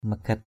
/mə-ɡ͡ɣɛt˨˩/ (d.) ả = fille. girl (informal, not respect). magait nan m=gT nN ả đó, con đó. cette fille là. this (that) girl. magait ban halei? m=gT bN hl]? con...